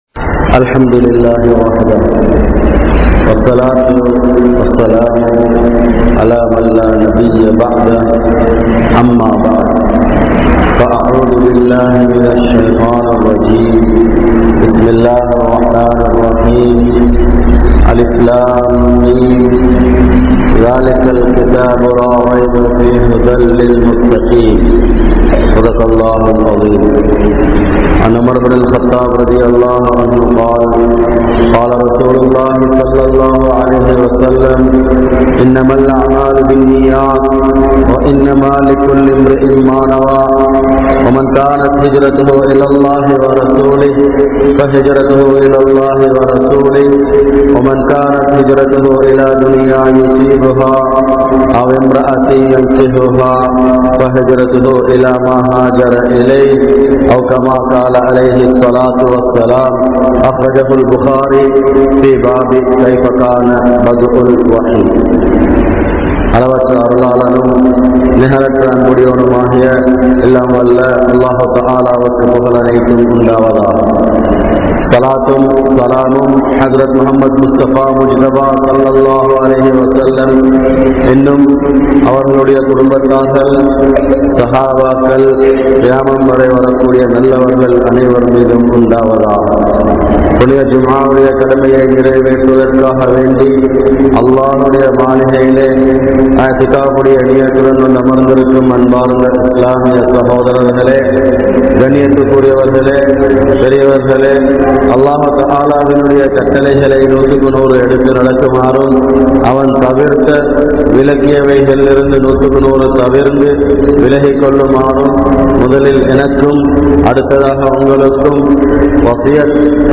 Indraya Petroar Pillaihalukku Idaielaana Uravu (இன்றைய பெற்றோர் பிள்ளைகளுக்கு இடையிலான உறவு) | Audio Bayans | All Ceylon Muslim Youth Community | Addalaichenai
Umbitchi Jumua Masjidh